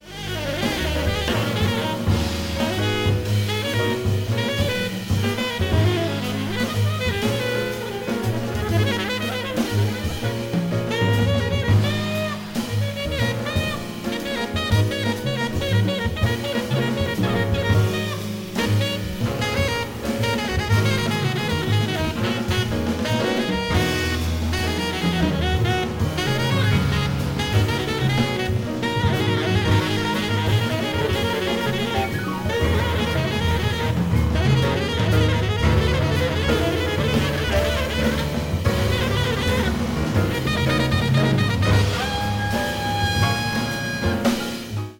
Genre: Jazz
Style: Post Bop, Modal
File under: Japanese Jazz